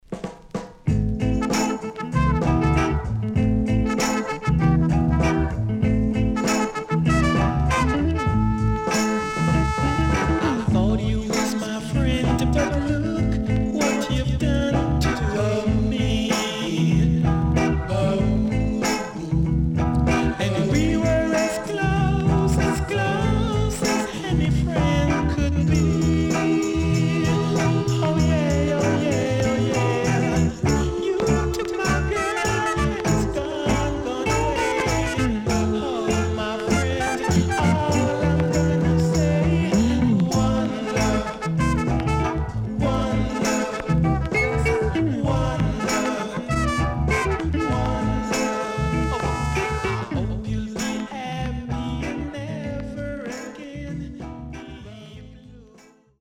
哀愁漂うEarly Reggaeの名曲の数々を収録した名盤
SIDE A:全体的にチリプチノイズ入ります。